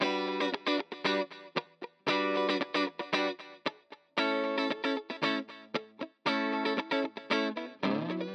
01 Guitar PT2.wav